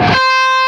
LEAD C 4 LP.wav